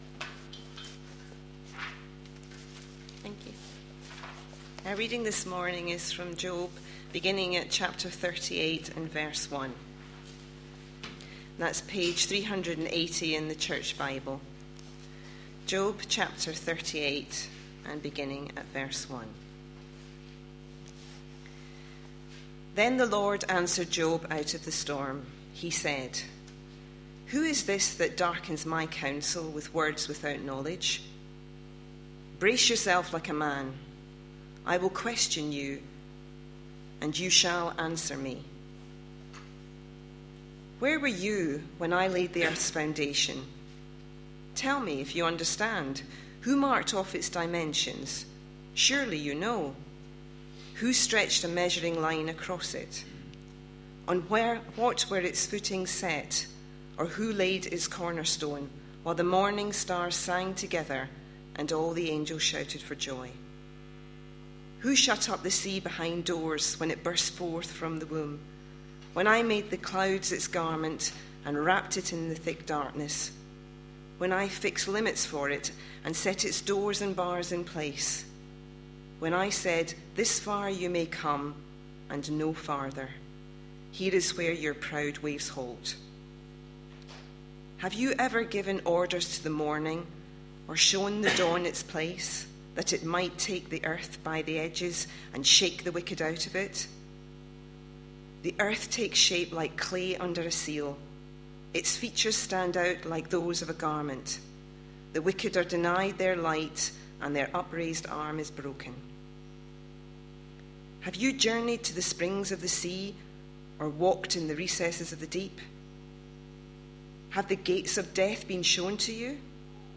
A sermon preached on 20th January, 2013, as part of our The gospel is the reason series.